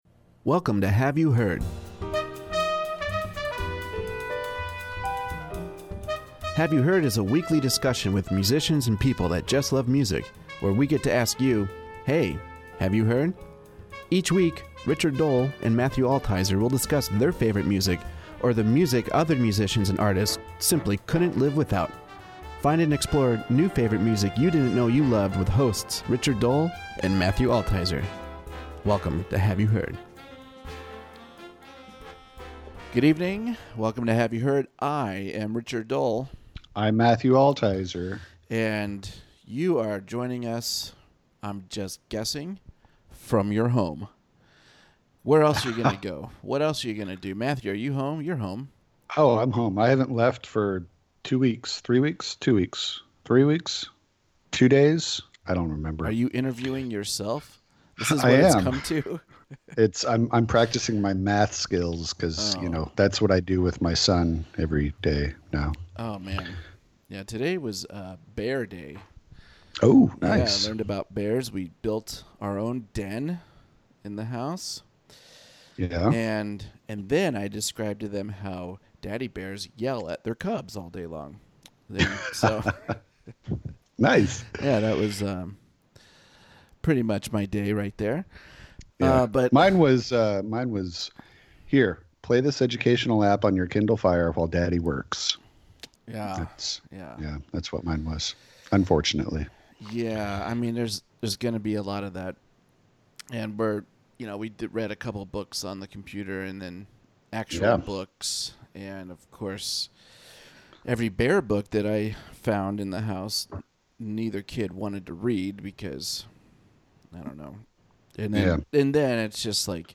jazz
on guitar
on alto sax
on tenor sax